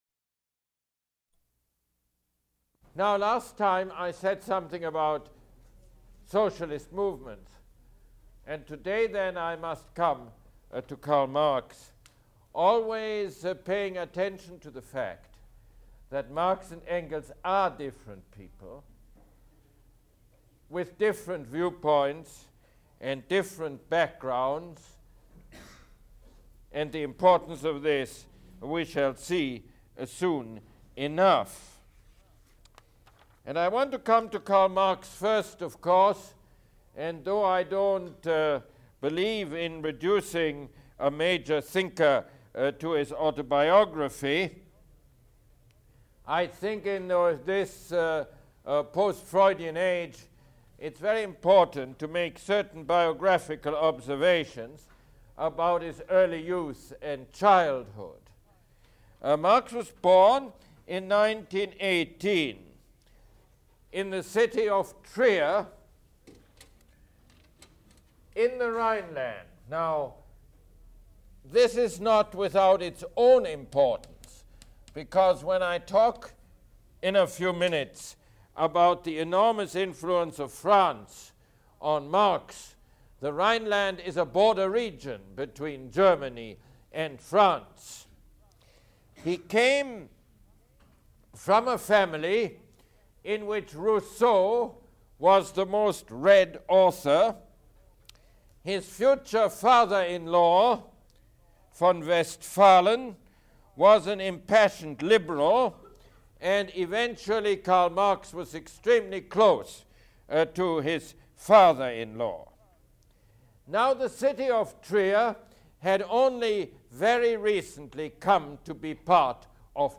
Mosse Lecture #29